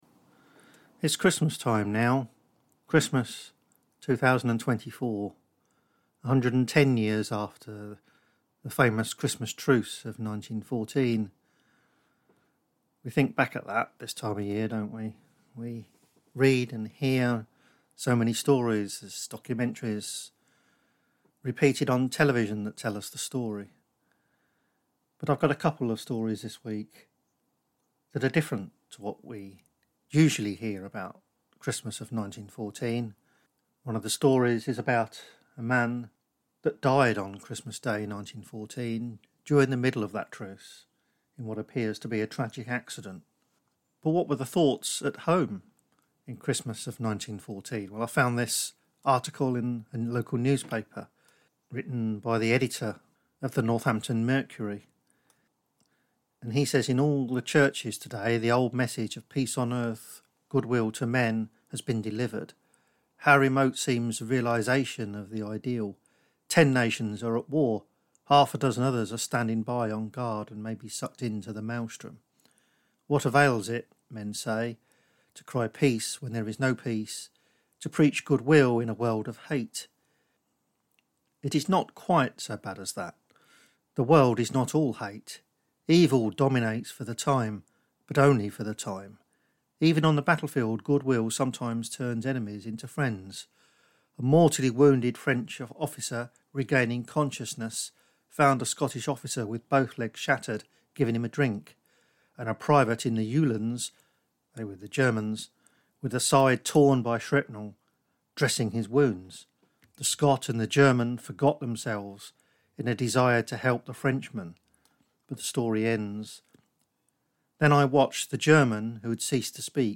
And we hear the voice of a veteran who was there.